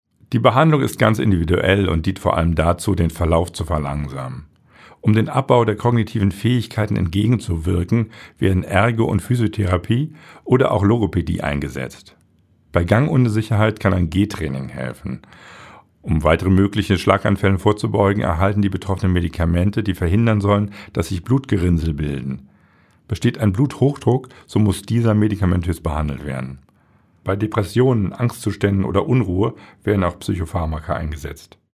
O-Töne04.09.2024